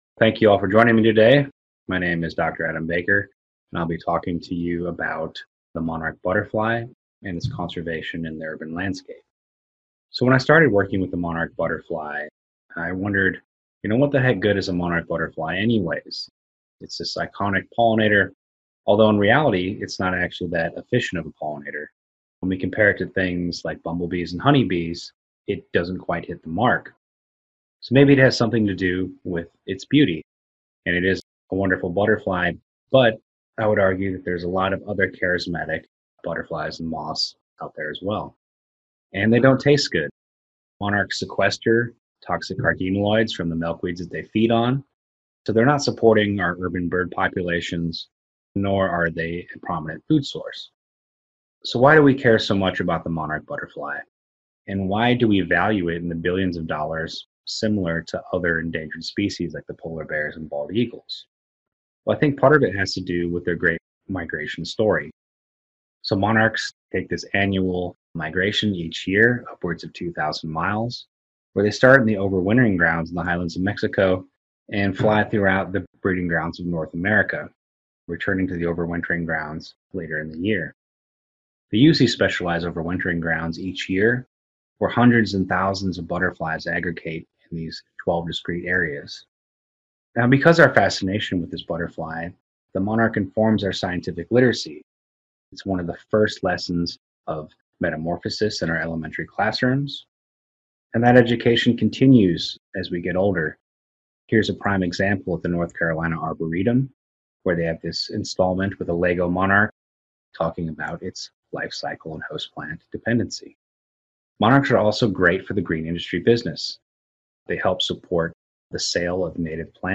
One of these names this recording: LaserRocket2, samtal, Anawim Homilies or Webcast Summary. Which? Webcast Summary